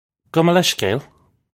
Pronunciation for how to say
Go muh lesh-kale. (U)
This is an approximate phonetic pronunciation of the phrase.